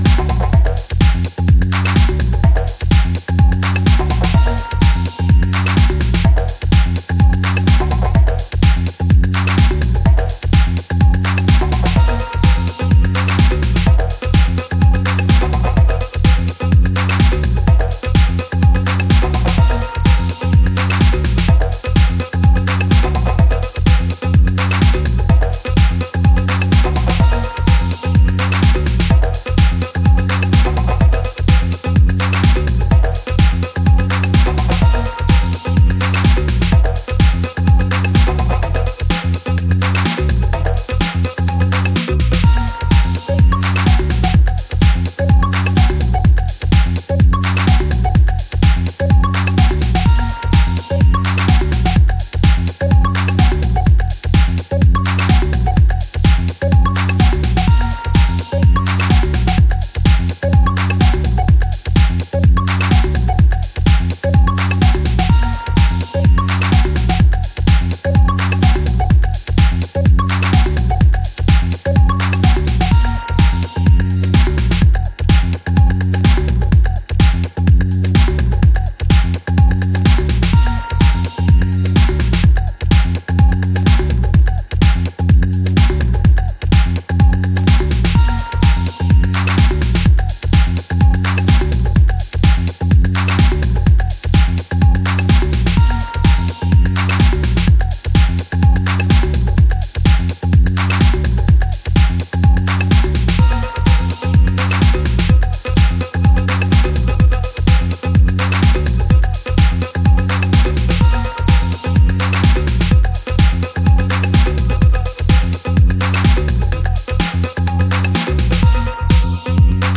ソリッドに跳ねるニュースクール・テック・ハウスを展開しており
低いところから牽引するベースラインやユーフォリックなシンセがフロアを沸かせる